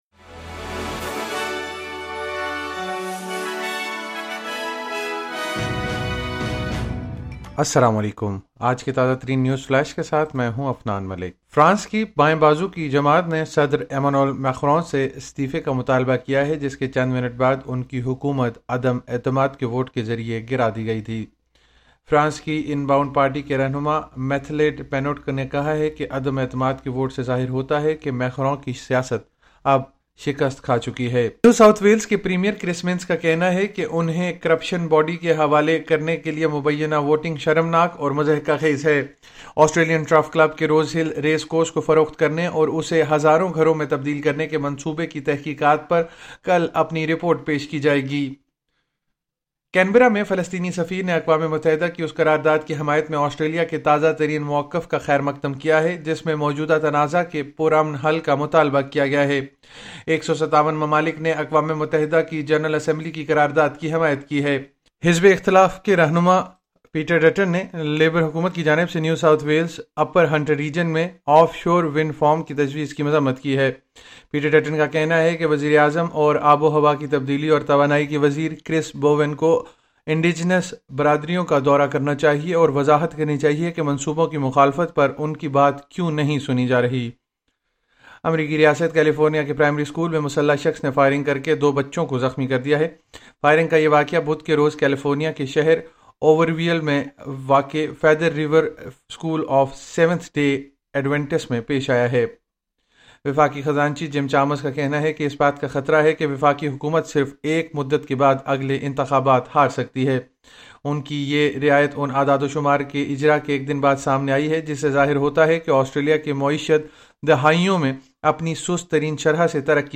اردو نیوز فلیش:05 دسمبر 2024